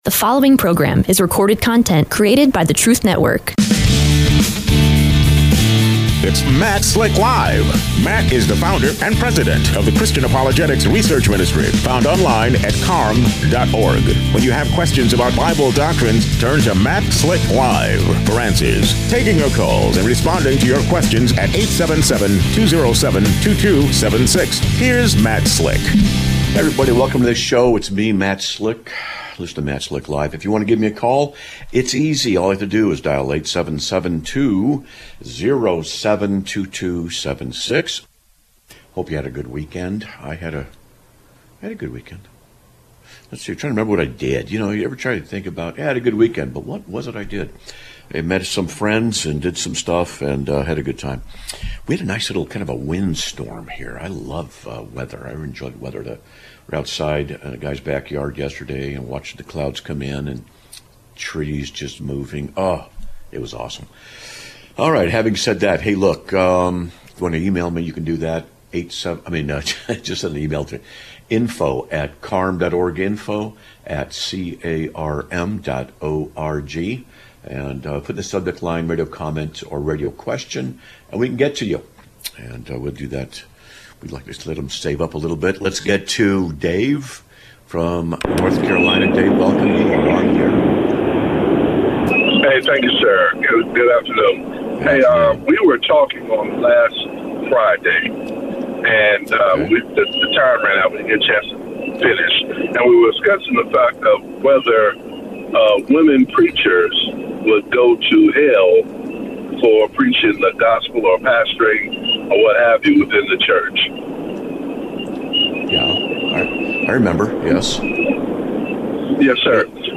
Live Broadcast of 08/04/2025
A Caller Tries to "Steam-Roll" the Conversation